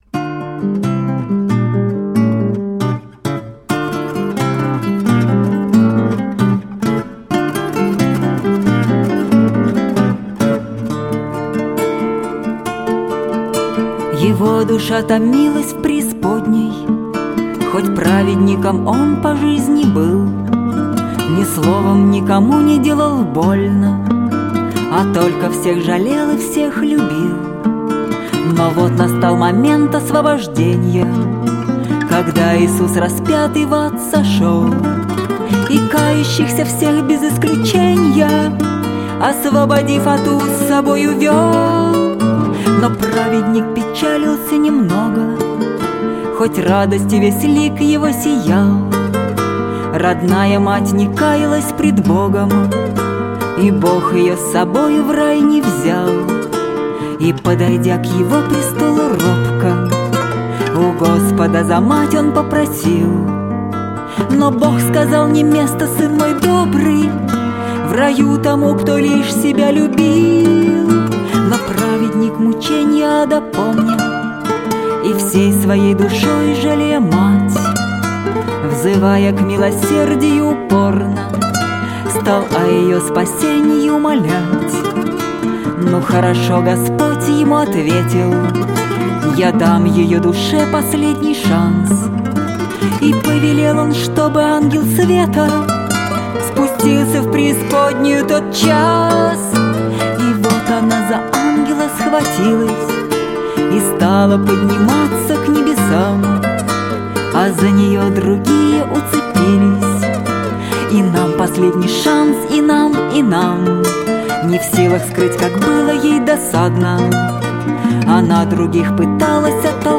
гитара, мандолина.